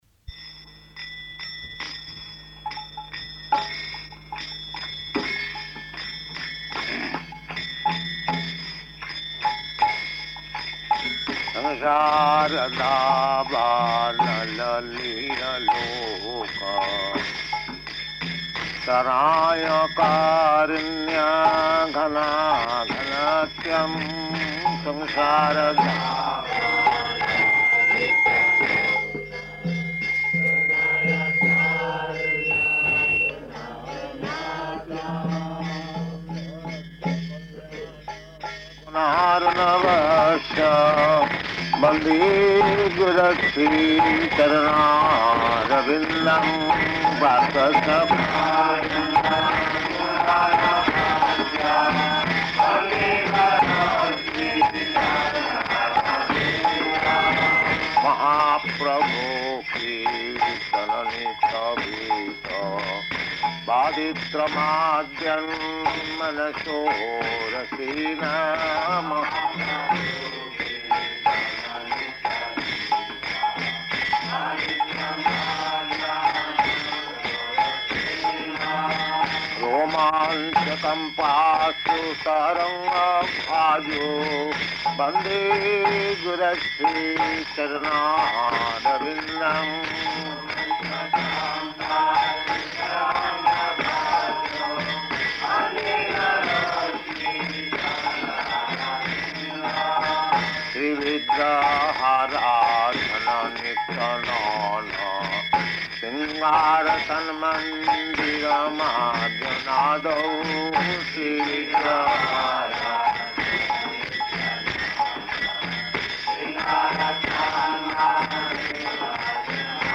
Location: London
[leads singing of Gurvaṣṭaka prayers]